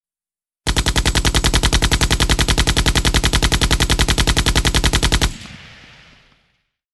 Звуки пулемёта
Грохот пулеметных выстрелов